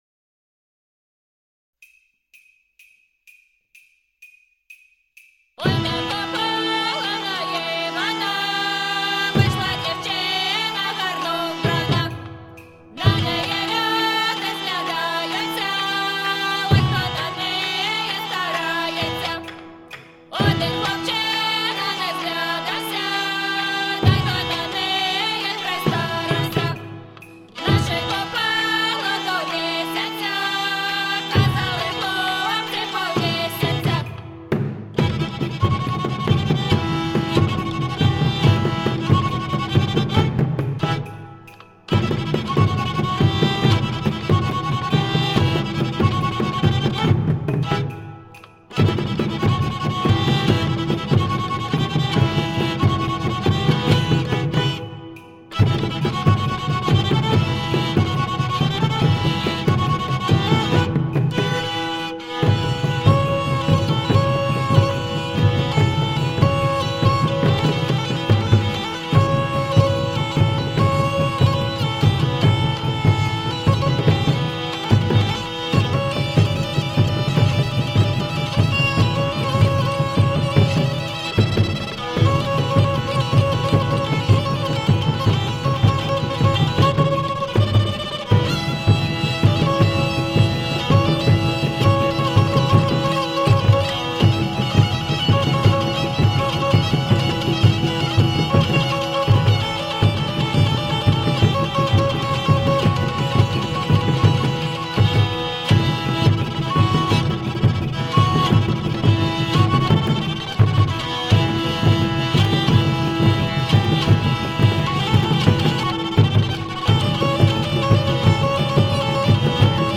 Tagged as: World, Folk, Eastern Voices